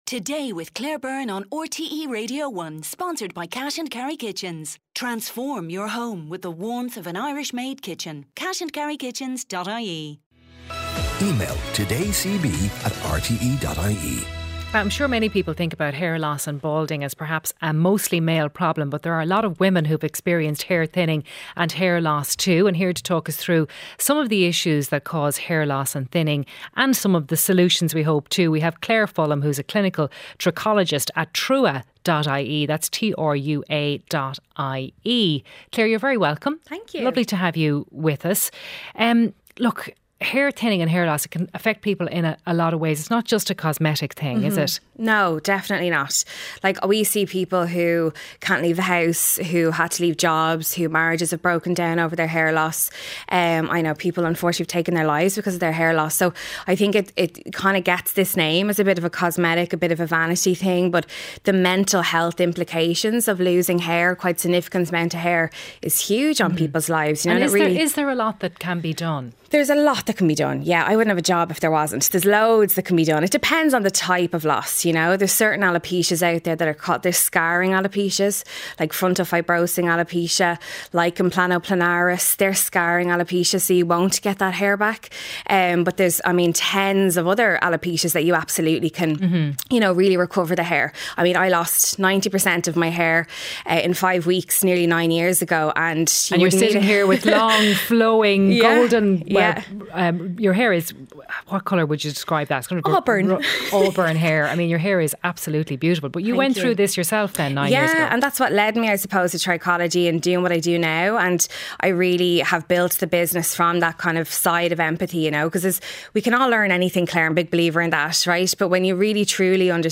Highlights from the mid-morning show with Claire Byrne, featuring stories of the day, sharp analysis, features, sports and consumer interest items.